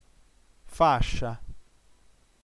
fàscia nf fà.scia - ['faʃa] ◊